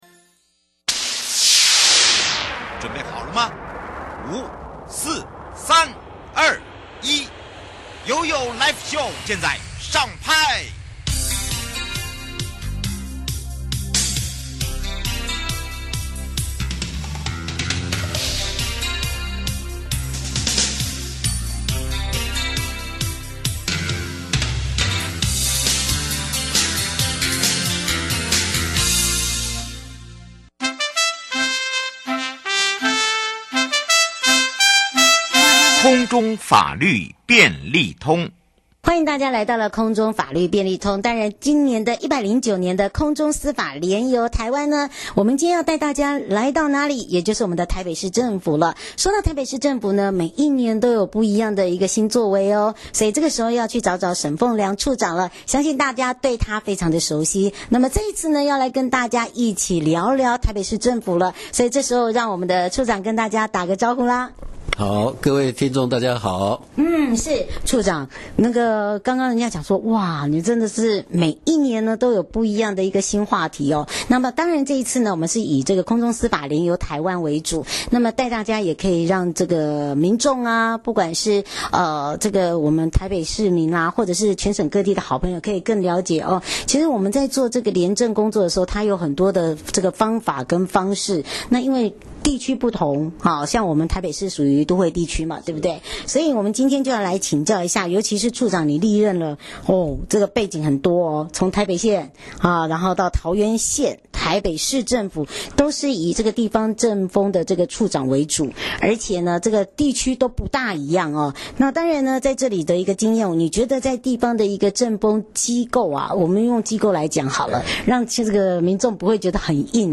受訪者： 1.空中司法廉遊台灣-台北市政府處長 您曾歷任台北縣政府、桃園縣(市)政府等地方正風首長，請問由多年